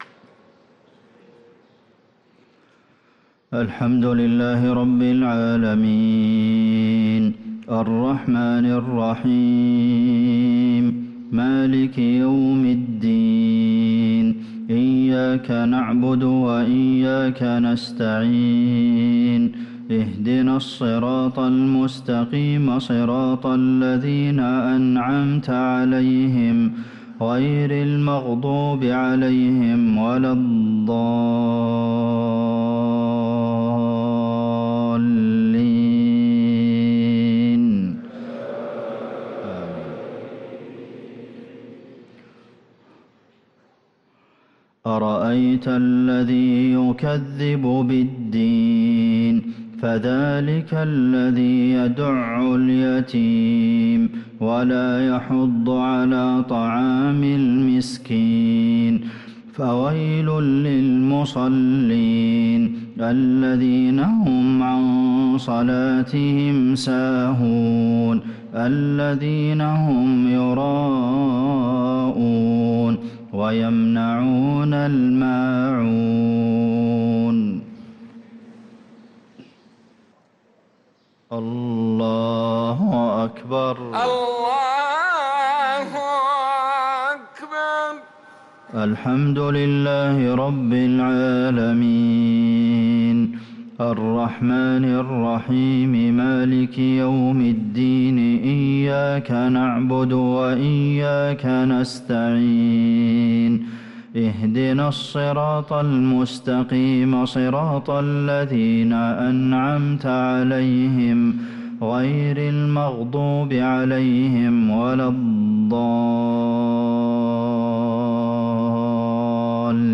صلاة المغرب للقارئ عبدالمحسن القاسم 3 جمادي الأول 1445 هـ
تِلَاوَات الْحَرَمَيْن .